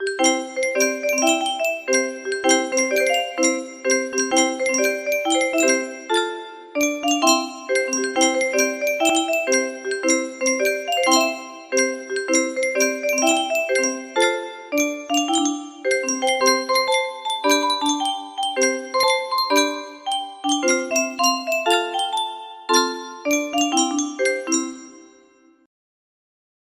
Goes the Weasel! music box melody